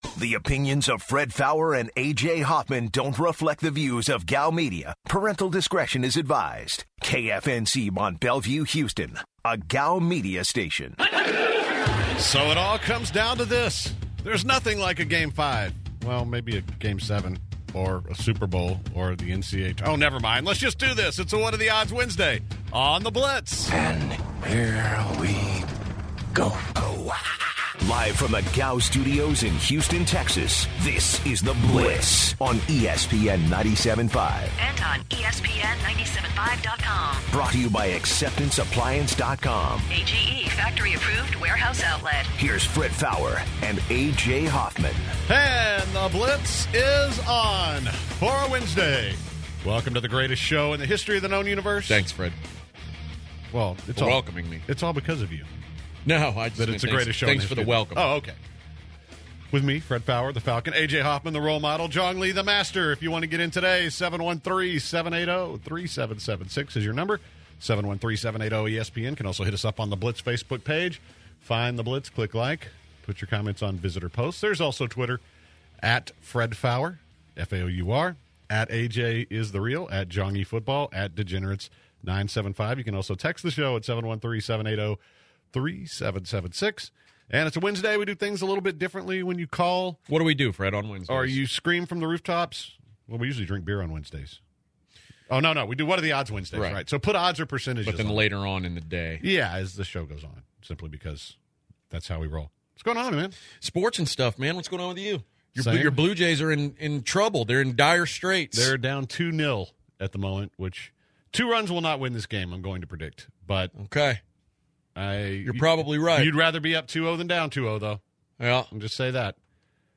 The guys start the show off by previewing Astros and Royals game 5. They also go over Astros personnel and discuss what they need to improve. Brian Ching, managing director for Houston Dash and former Dynamo forward joins the show.